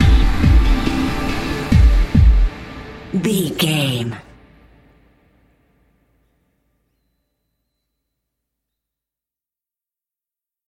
Ionian/Major
D
synthesiser
drum machine